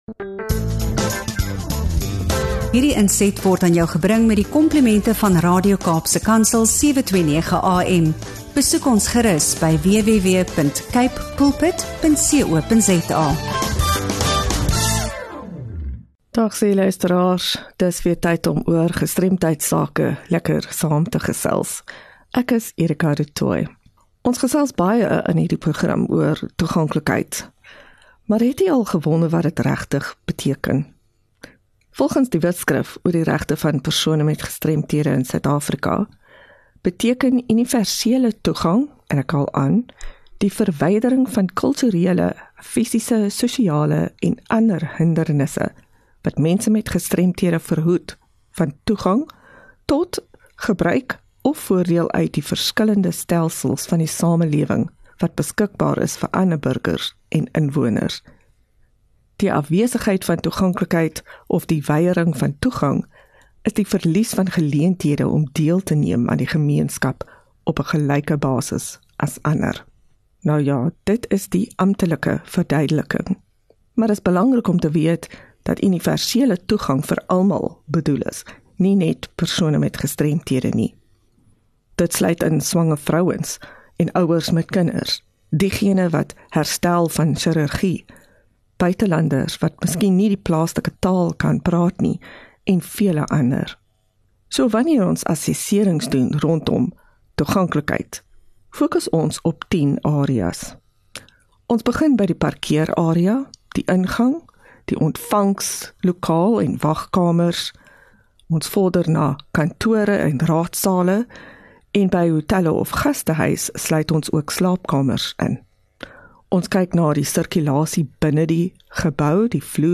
1 Apr GEMEENSKAP GESTREMDHEID - 'n Gesprek oor Geestlike Gestremdhede en Universele Toegang in Suid-Afrika